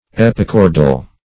Search Result for " epichordal" : The Collaborative International Dictionary of English v.0.48: Epichordal \Ep`i*chor"dal\, a. [Pref. epi- + chordal.] (Anat.) Upon or above the notochord; -- applied esp. to a vertebral column which develops upon the dorsal side of the notochord, as distinguished from a perichordal column, which develops around it.